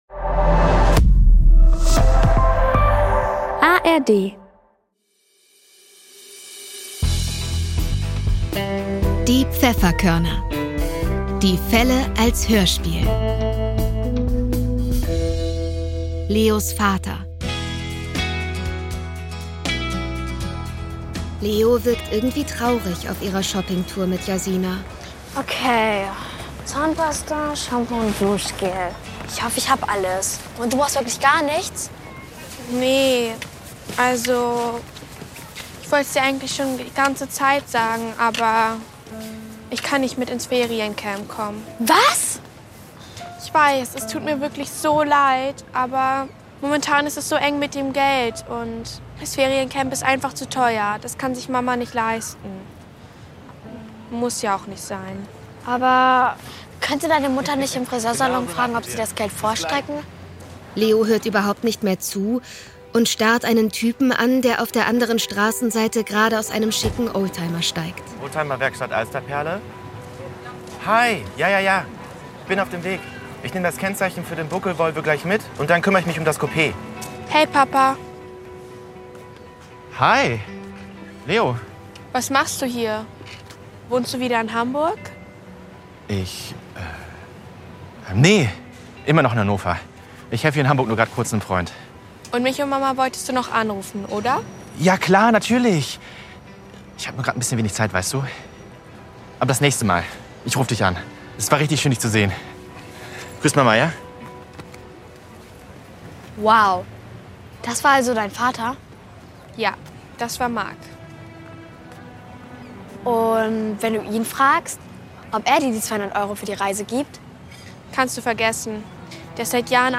Leos Vater (22/26) ~ Die Pfefferkörner - Die Fälle als Hörspiel Podcast